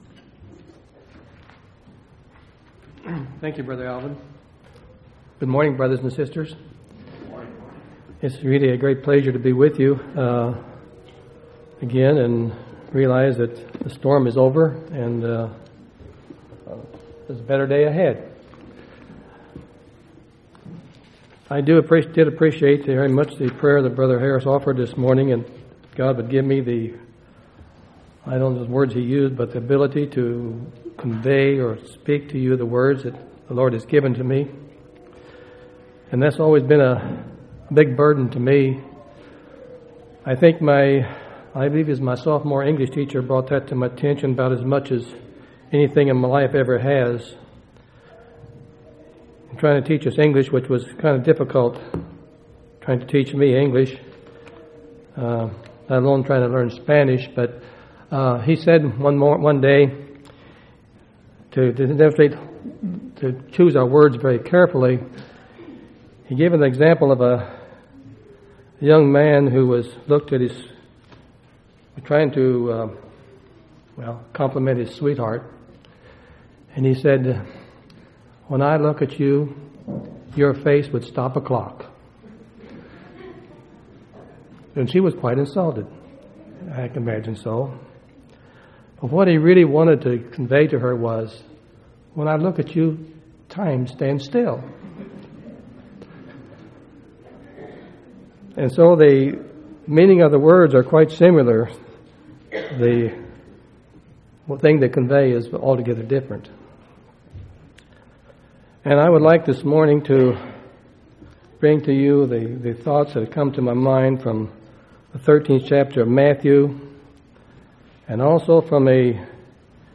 1/9/2005 Location: Temple Lot Local Event